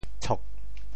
潮州发音 潮州 cog4